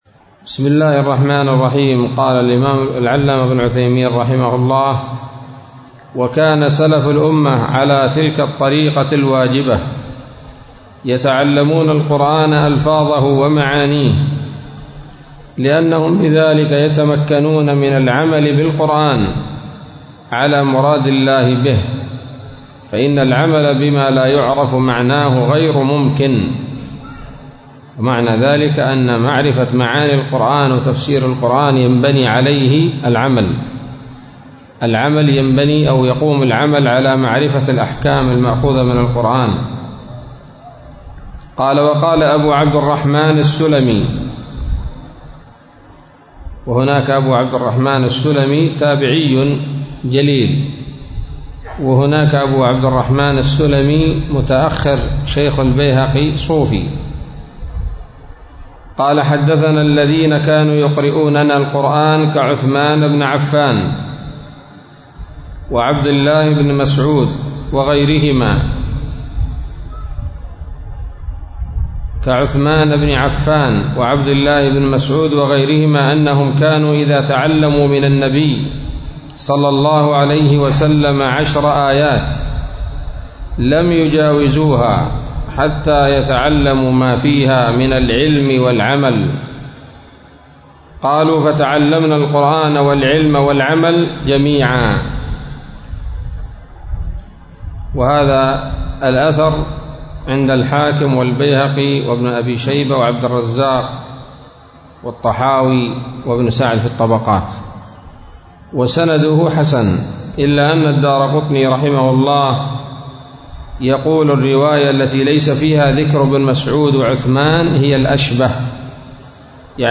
الدرس الثامن عشر من أصول في التفسير للعلامة العثيمين رحمه الله تعالى